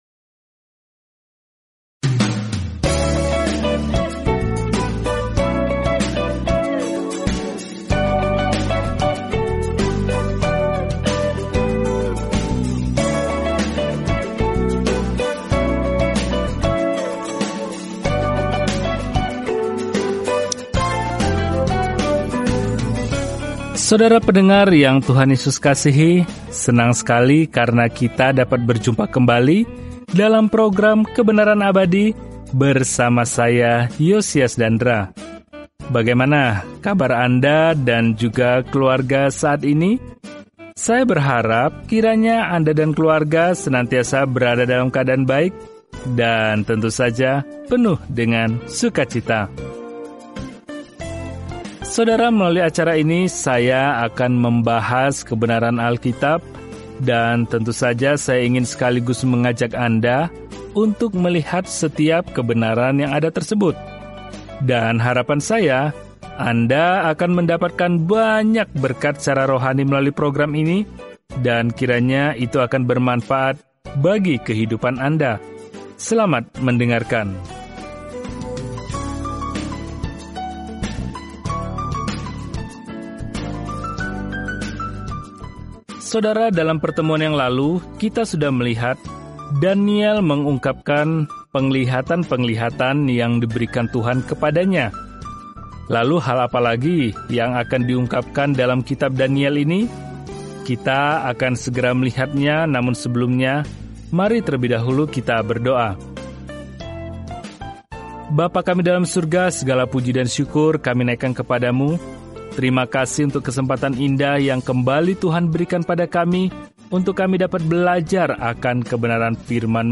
Firman Tuhan, Alkitab Daniel 7:7-13 Hari 17 Mulai Rencana ini Hari 19 Tentang Rencana ini Kitab Daniel merupakan biografi seorang pria yang percaya kepada Tuhan dan visi kenabian tentang siapa yang pada akhirnya akan memerintah dunia. Telusuri Daniel setiap hari sambil mendengarkan studi audio dan membaca ayat-ayat tertentu dari firman Tuhan.